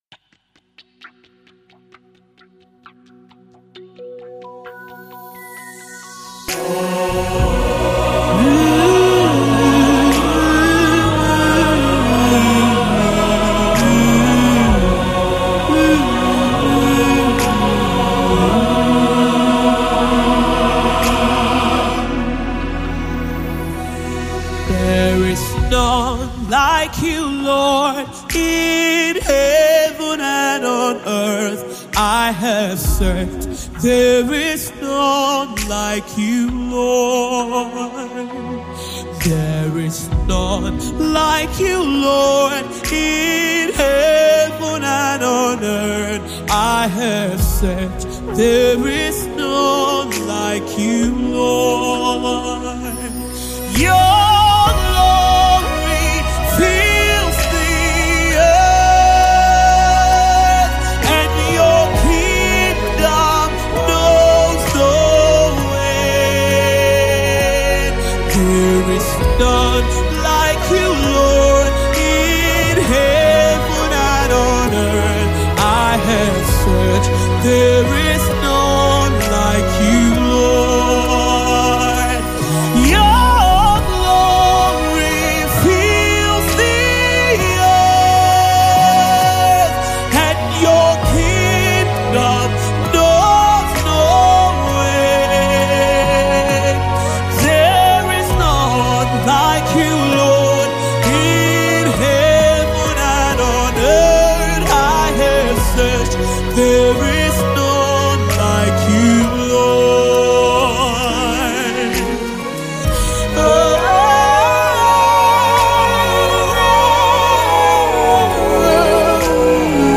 a soul-stirring gospel project with 10 powerful tracks
With her strong voice and heartfelt lyrics